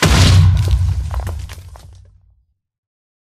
explode2